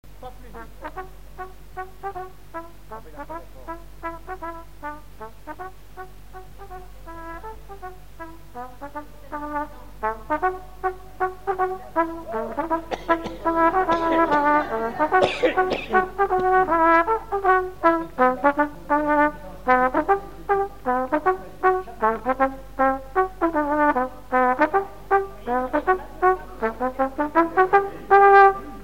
Mazurka
Triaize ( Plus d'informations sur Wikipedia ) Vendée
Résumé instrumental
danse : mazurka
Pièce musicale inédite